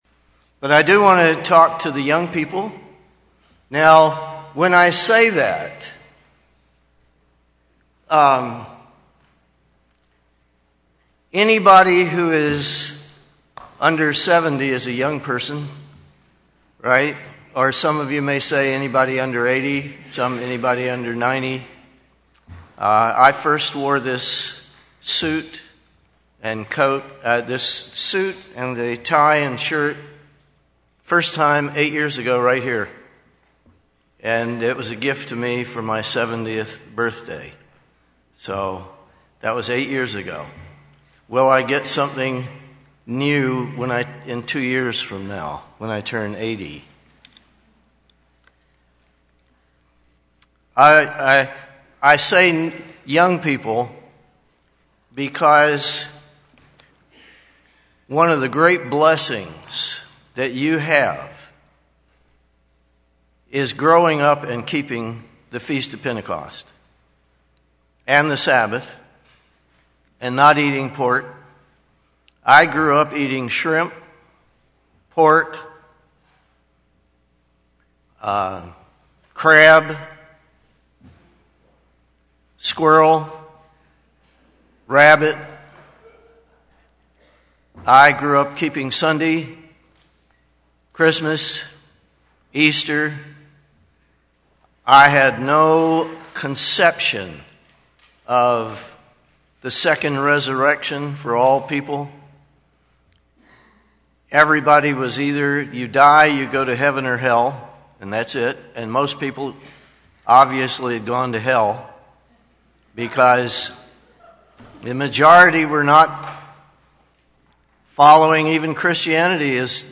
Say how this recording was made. What is the meaning of the Day of Pentecost, and why do we observe it in the Church today? This sermon answers those questions with a cursory examination of the scriptures. This message was given on the Feast of Pentecost 2018.